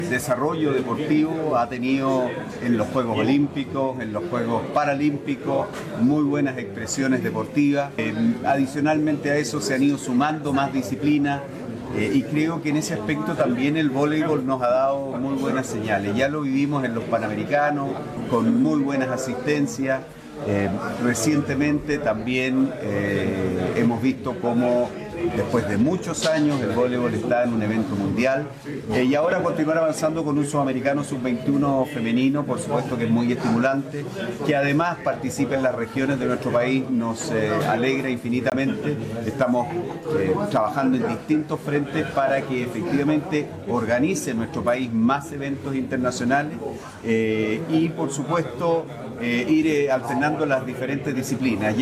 El jefe de la cartera de Deportes, indicó que durante los últimos años nuestro país ha logrado convertirse en un punto importante a nivel continental, lo que además permite descentralizar los eventos internacionales.